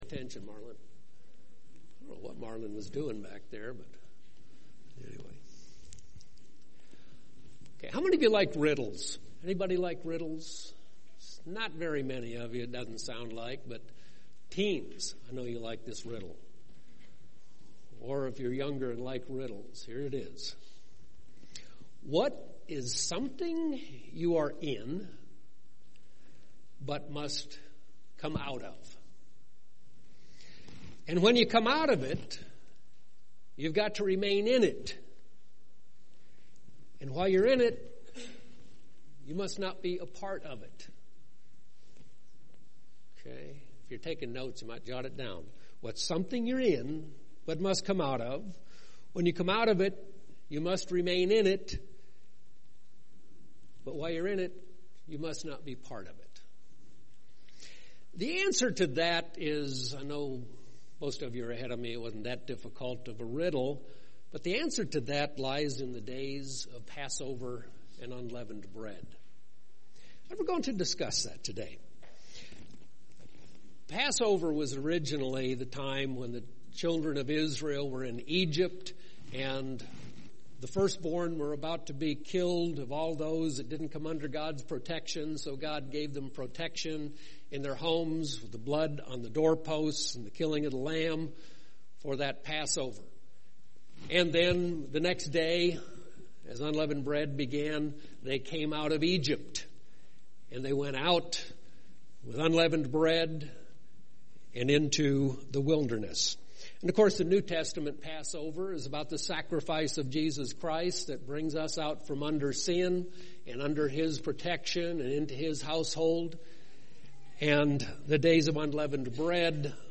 This sermon discusses de-leavening our lives in a leavened world.